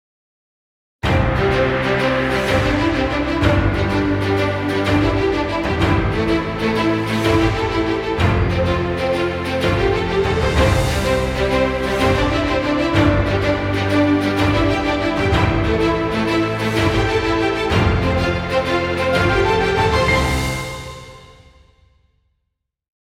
Epic music, exciting intro, or battle scenes.
Background Music.